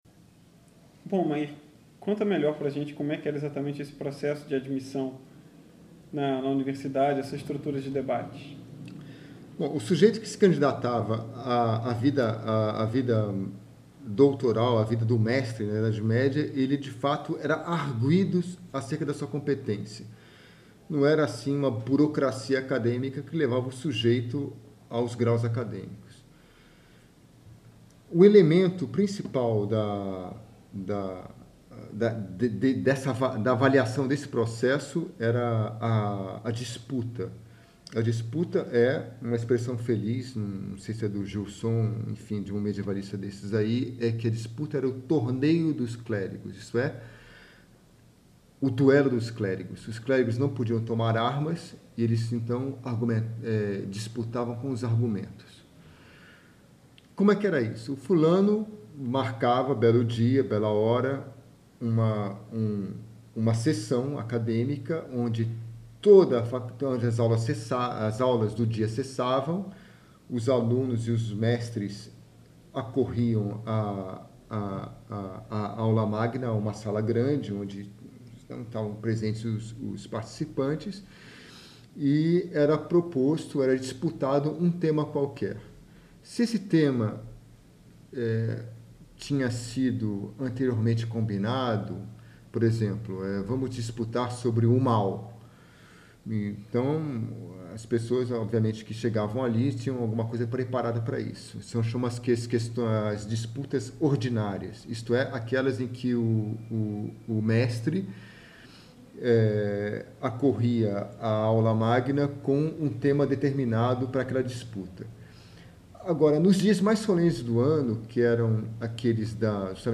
uma entrevista